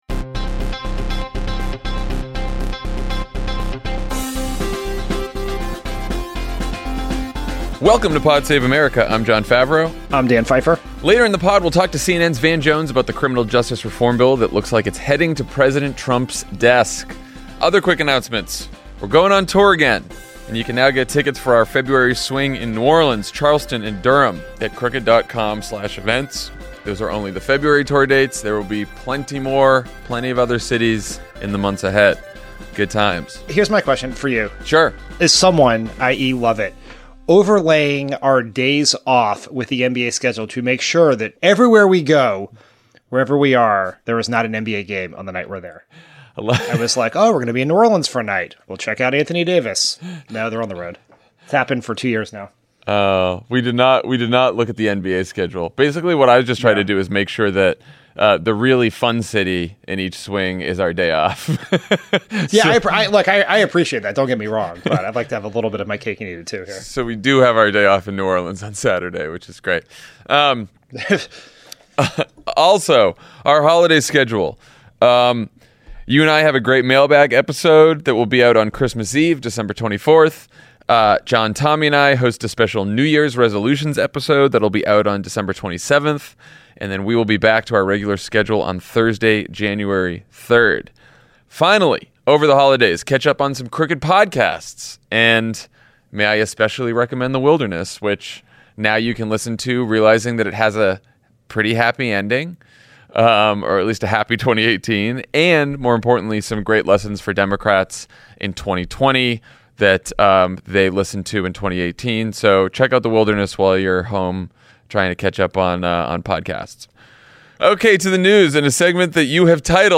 A judge’s shock over Michael Flynn’s crimes destroys right-wing conspiracy theories, Trump is forced to shut down his fraudulent charity, Trump may get his government shutdown after all, and a criminal justice reform bill just may become law. Then CNN’s Van Jones talks to Jon and Dan about what’s in the First Step Act, and how a bipartisan coalition got it done.